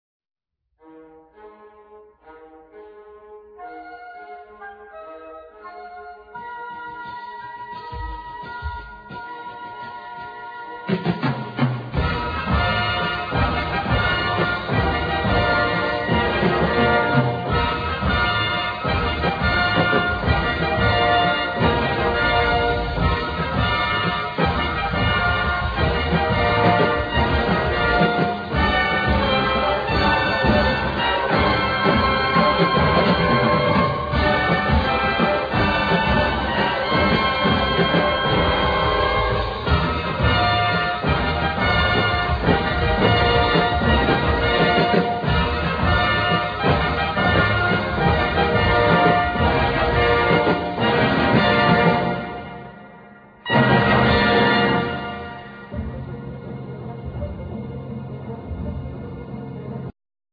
Keyboards,Piano
Bass
Drums
Guitar, Guitar-synth, Synthsizer, Arp Avatar